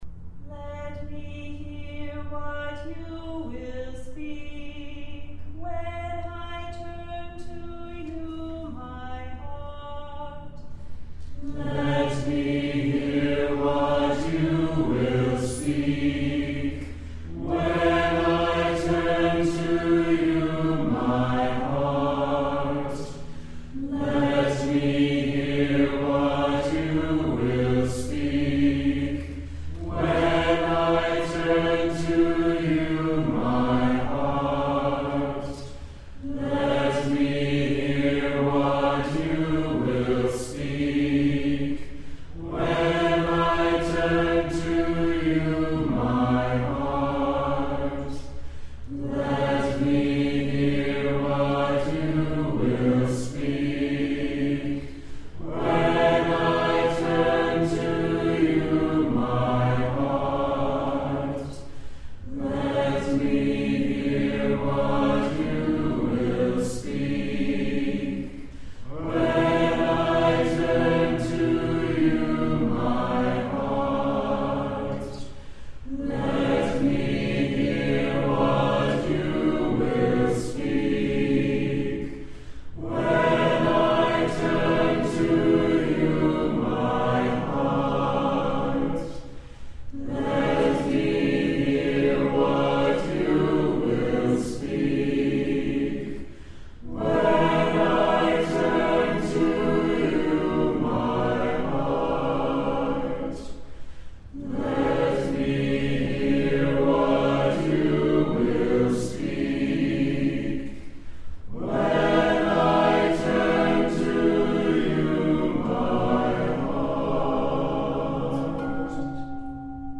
Chant: Let me hear what you will speak when I turn to you my Heart
Readings from last week's Daily Contemplative Pauses